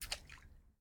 water-splash-13
bath bathroom bubble burp click drain dribble dripping sound effect free sound royalty free Nature